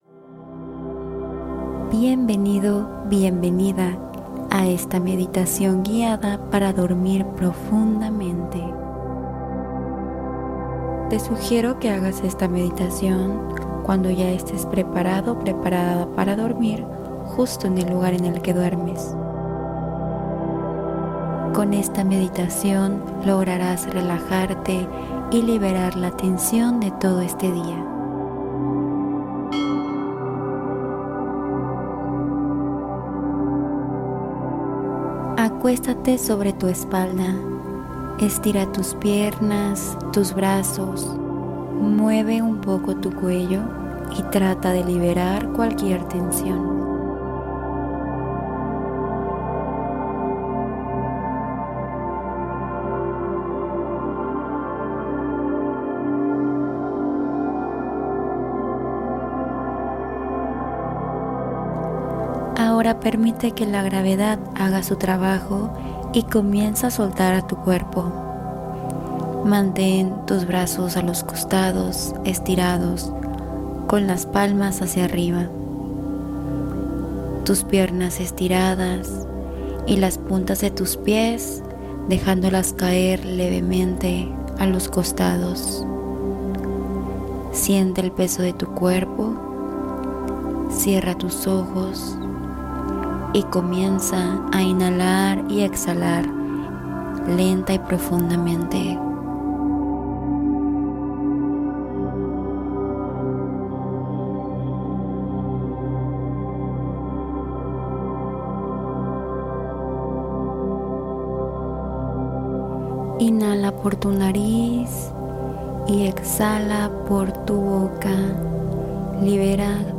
Relajación Profunda Guiada para Soltar el Estrés Acumulado